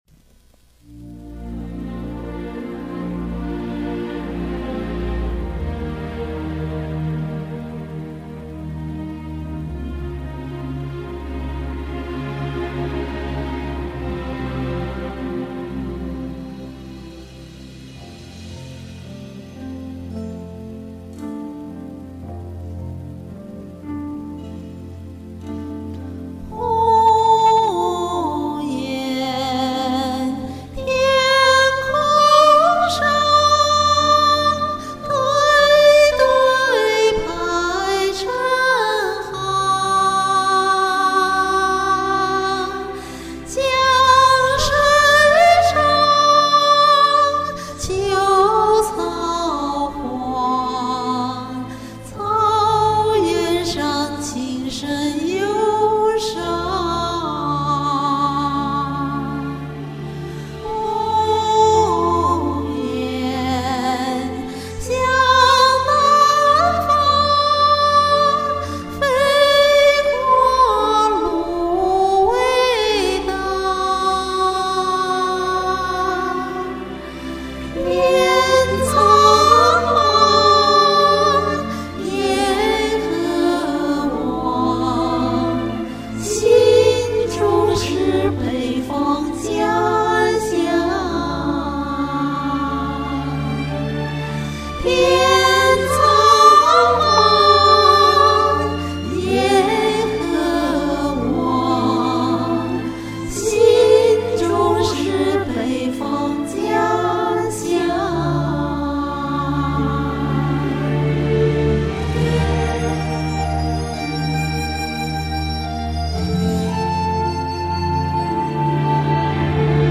唱得多好啊～～～深情細膩，動人心扉。聲線特別美！
準確來說應該是自合重唱